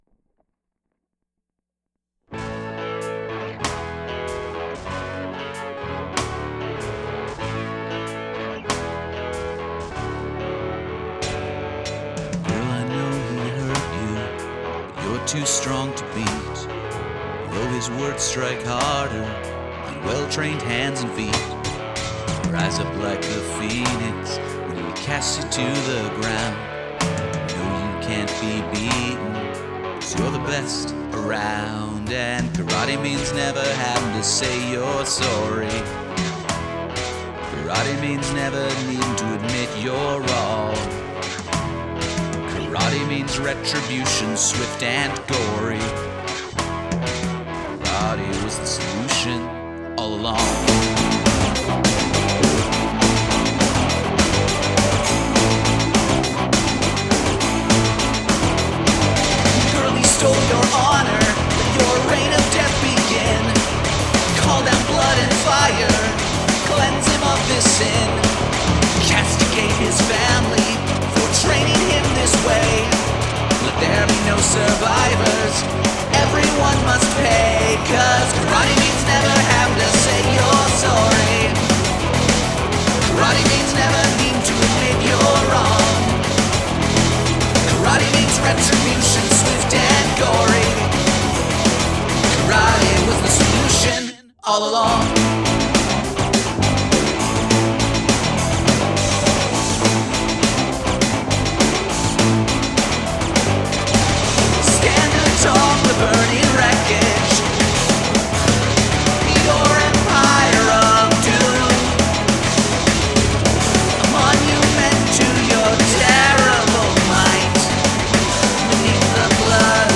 I'm not hearing bass or kick at all. Very mid-rangy.
It comes in nicely at 2:25 mark.
Snare is above the mix, maybe a little too much.
Once the song gets going they sit in the mix good.